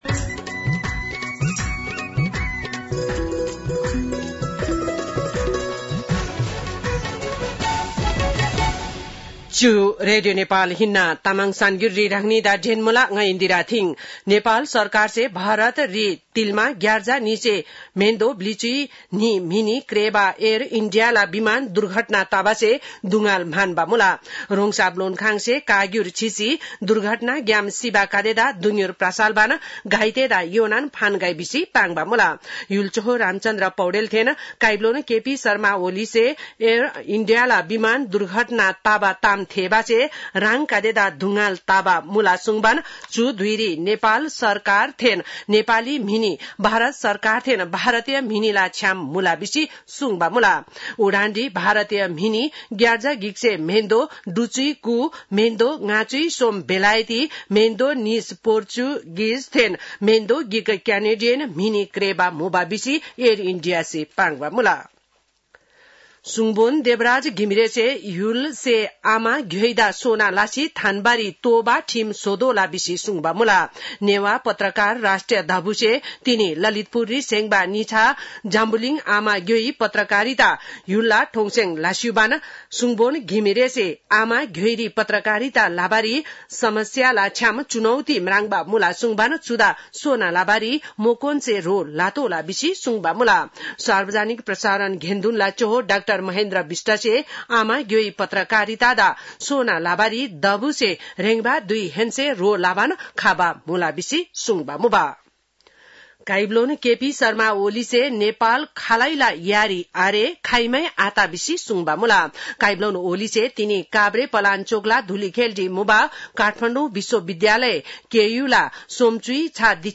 तामाङ भाषाको समाचार : ३० जेठ , २०८२